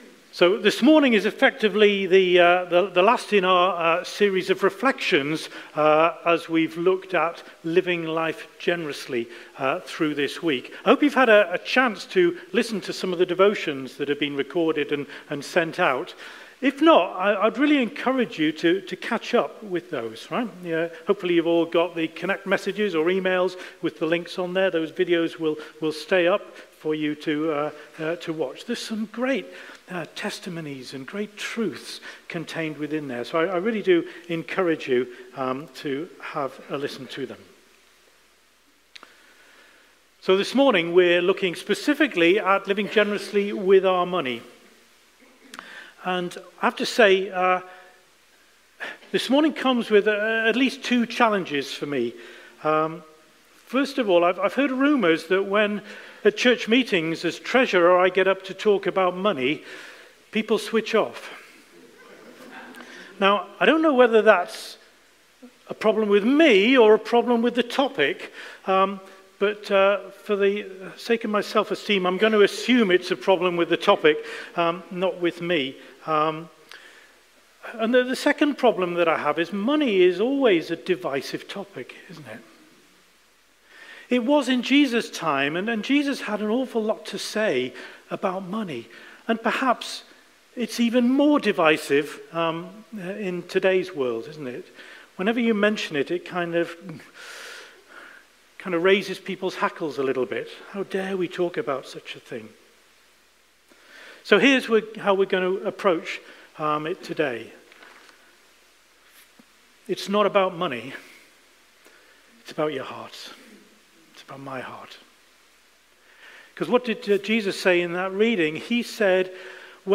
Live stream Passage: Matthew 6:19-24 Service Type: Sunday Morning